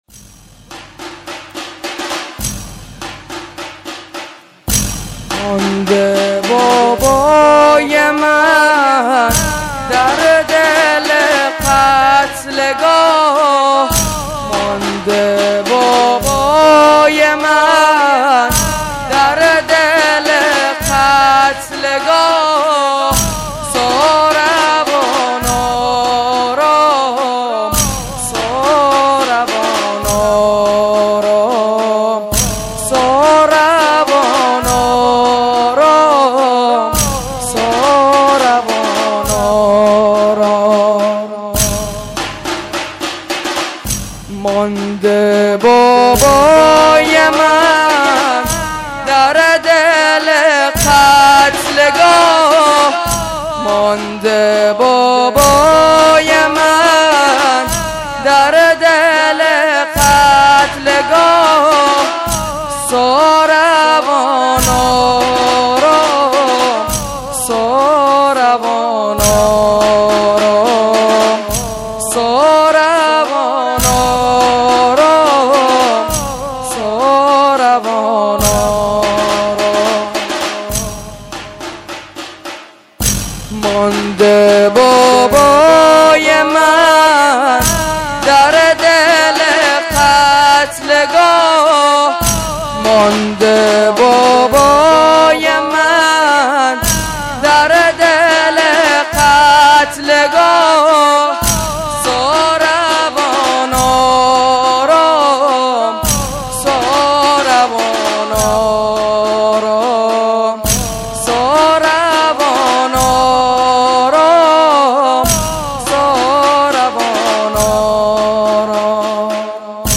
هیئت رهروان شهدا شهرستان دزفول
زنجیرزنی (ساربان آرام) شب کفن و دفن محرم الحرام سال ۱۴۴۲